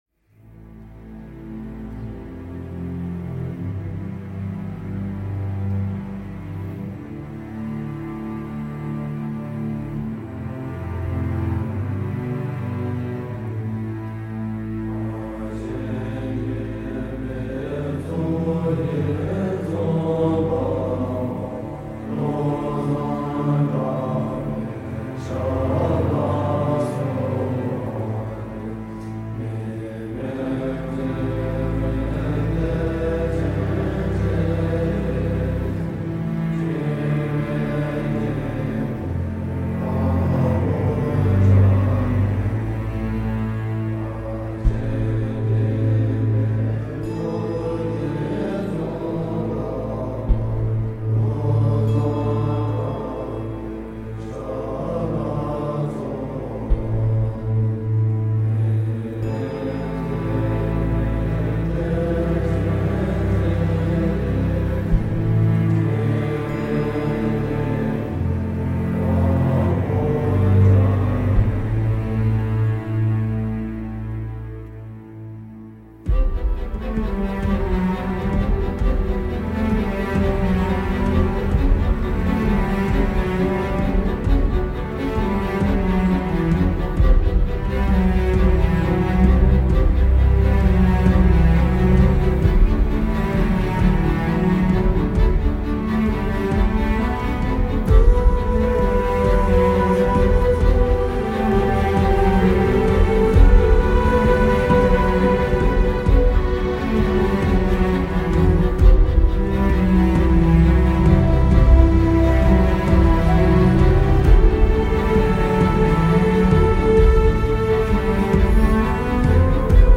Anxiogène, et donc réussi.